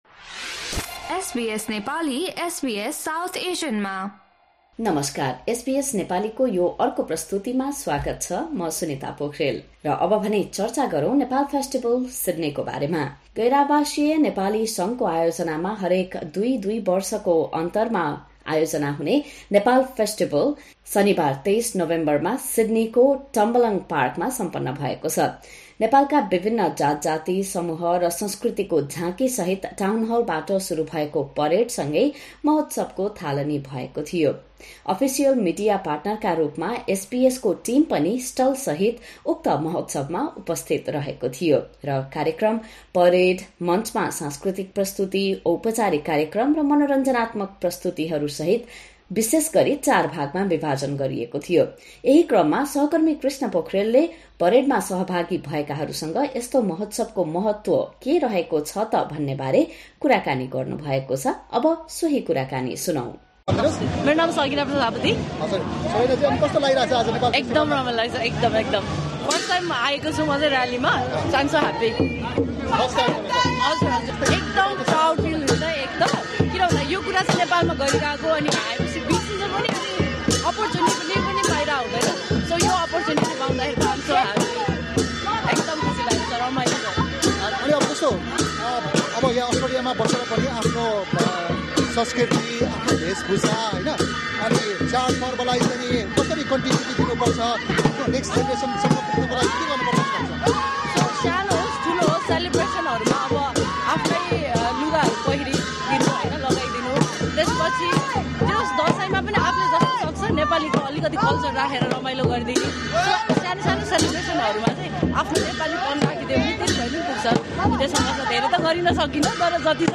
Participants shared their joy and experiences, remarking on how the festival created a sense of Nepal away from home.